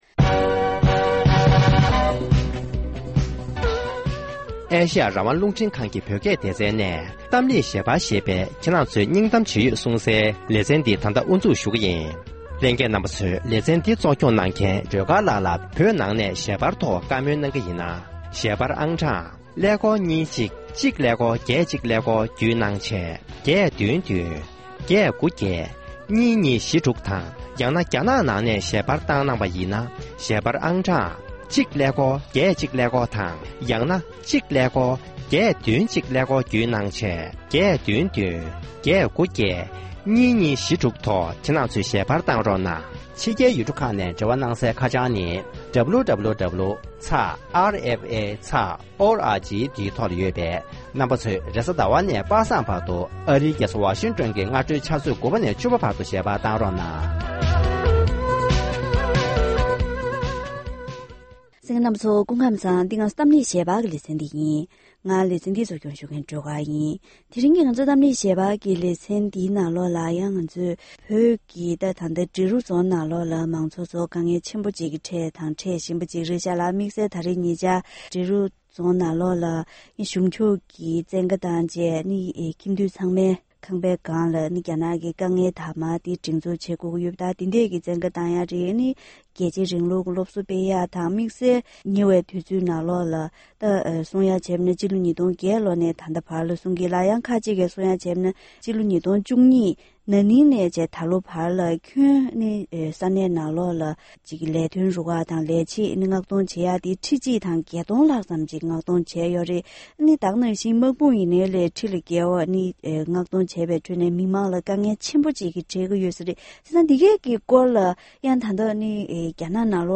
དེ་རིང་གི་གཏམ་གླེང་ཞལ་པར་ལེ་ཚན་ནང་བོད་ཀྱི་འབྲི་རུ་ས་གནས་སུ་གཞུང་ཕྱོགས་ནས་ལས་བྱེད་ཕོན་ཆེན་མངགས་གཏོང་བྱས་ཏེ་རྒྱལ་གཅེས་རིང་ལུགས་ཀྱི་སློབ་གསོ་སྤེལ་བཞིན་པ་མ་ཟད། ད་དུང་ས་གནས་སུ་དམག་མི་ཁྲི་བརྒལ་བ་བཏང་ནས་མང་ཚོགས་ཁྲོད་དྲག་གནོན་བྱེད་བཞིན་ཡོད་པའི་ཞིབ་ཕྲའི་གནས་ཚུལ་འགྲེལ་བརྗོད་ཞུས་པ་ཞིག་གསན་རོགས་གནང་།